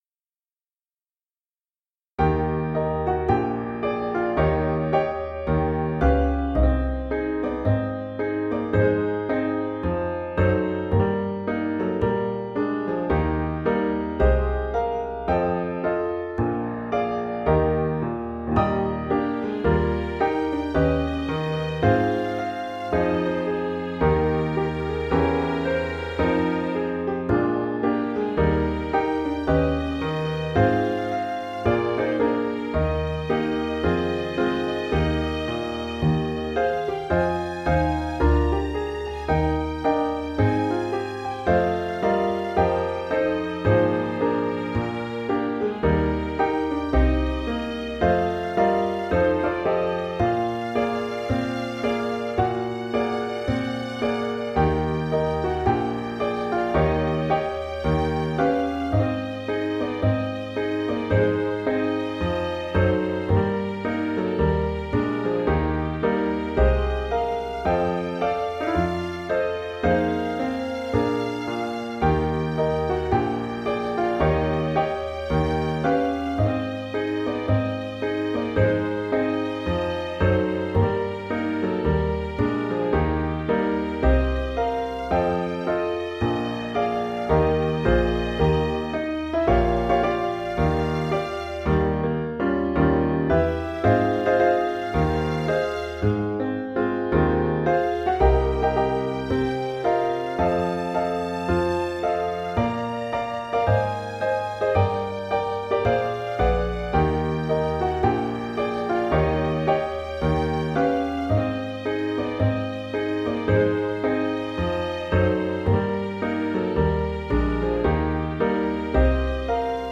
Virtual Accompaniment
easter-parade-Eb-VA.mp3